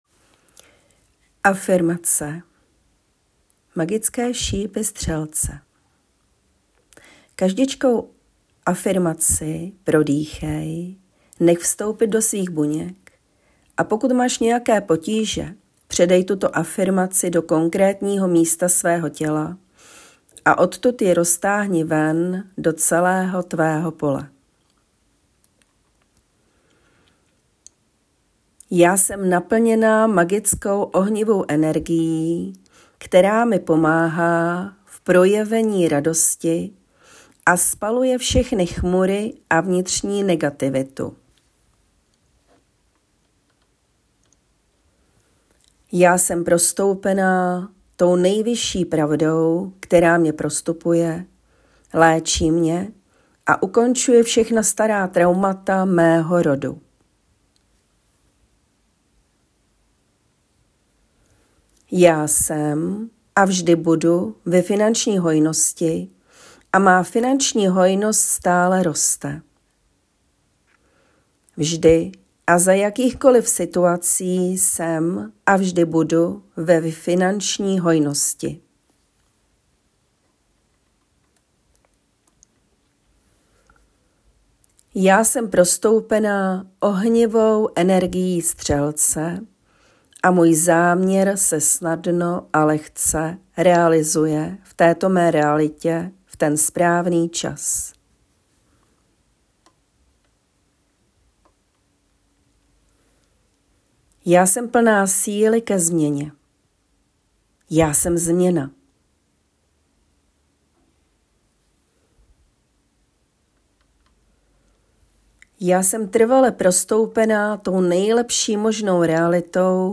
3. Afirmace - aktivují nové energie v nás (pouštět po meditaci kdykoliv, max 1x denně, min 3x za 14 dní)